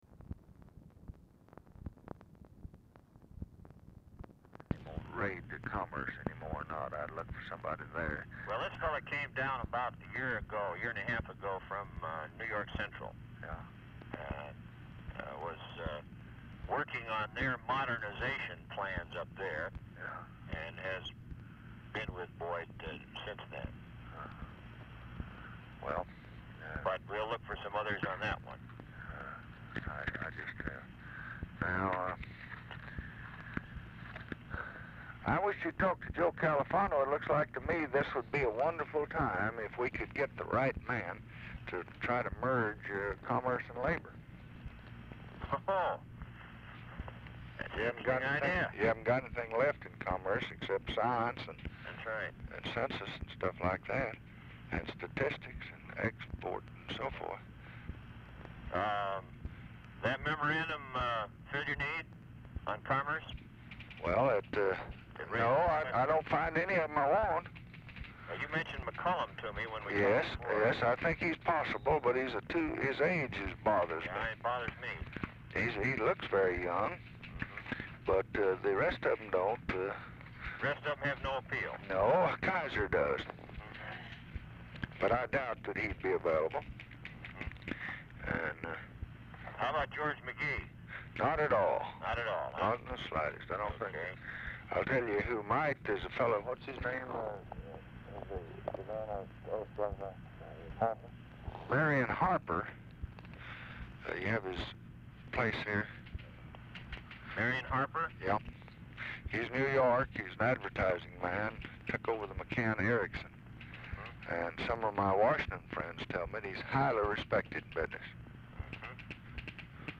Telephone conversation # 11224, sound recording, LBJ and JOHN MACY
LBJ SPEAKS WITH SOMEONE IN HIS OFFICE DURING CALL
Format Dictation belt
LBJ Ranch, near Stonewall, Texas